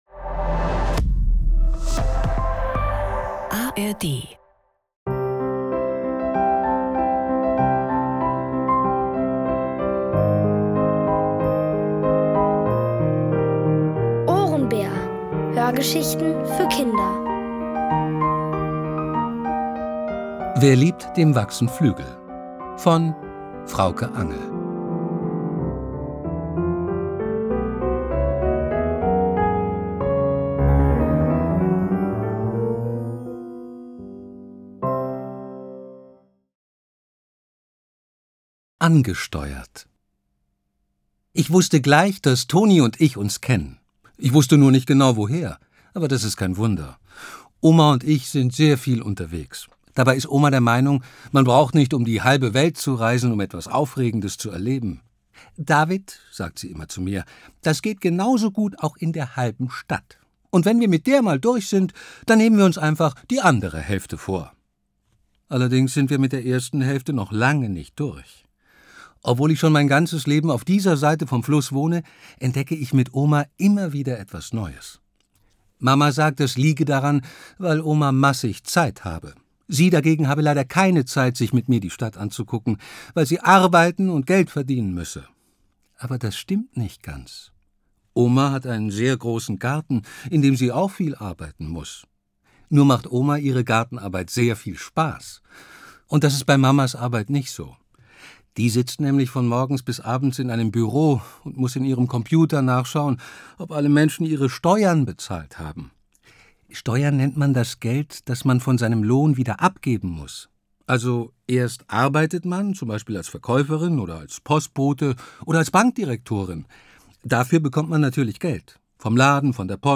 Wer liebt, dem wachsen Flügel | Die komplette Hörgeschichte!